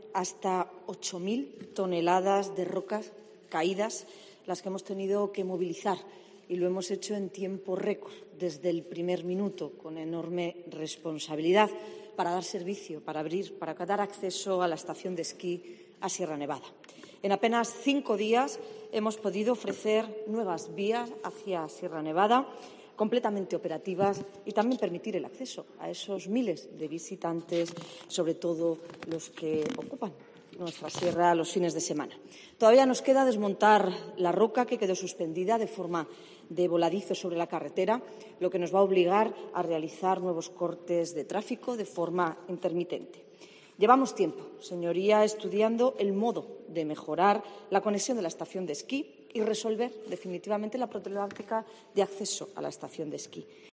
Marifrán Carazo ha hecho balance en el Pleno del Parlamento de Andalucía de los trabajos que se han acometido en la vía, incluídos los trabajos de retirada de rocas y la estabilización de la ladera que han permitido su rápida reapertura tras el suceso del 29 de enero.